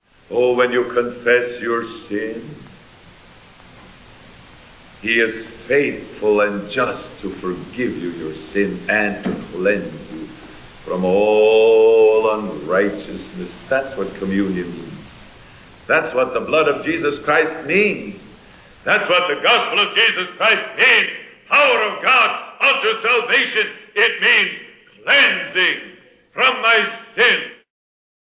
Audio Quality: Fair
We ran light hiss reduction, slowed the pitch to 106.25% of the original duration, ran the DeHummer, 8 filter, -30dB at 55.20Hz, another DeHummer 8 filter, -30dB at 57.90Hz, and compression.
There are many quiet clicks in the recording that have not been removed.